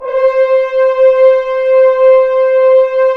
Index of /90_sSampleCDs/Roland LCDP06 Brass Sections/BRS_F.Horns 1/BRS_FHns Ambient
BRS F.HRNS0P.wav